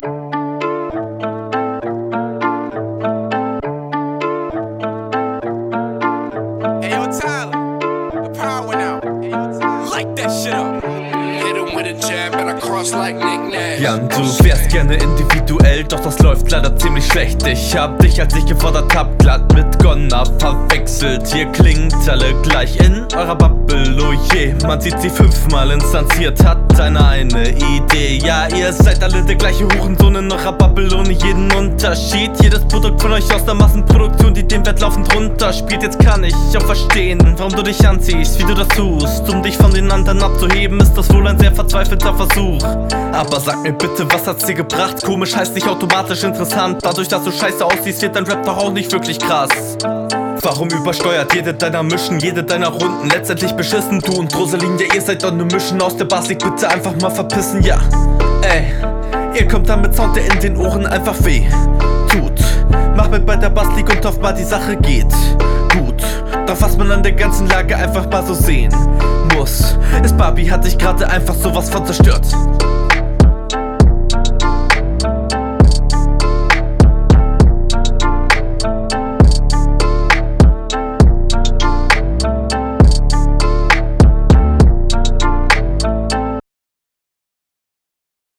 Ich mag, dass du hier was einzigartiges probierst mit dem 3/4-Takt.
Moin mein Bester, hast hier gute Flowvarriationen drin.
Ich liebe diesen Beat. 6/8tel ist so eine geile Taktart.